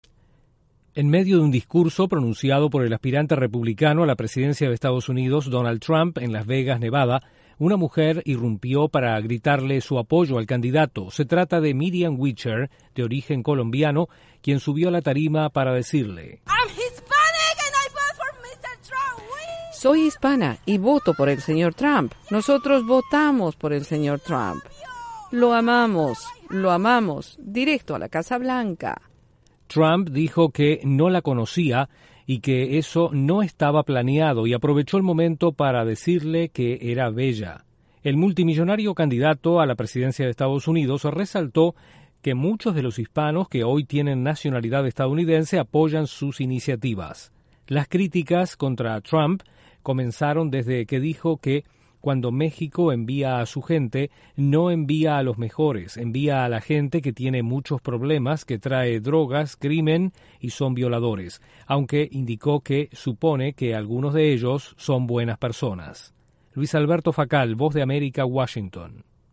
Una mujer de origen colombiano expresa efusivamente su apoyo al candidato a la Casa Blanca Donald Trump durante un acto de campaña.